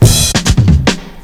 FILL 10   -R.wav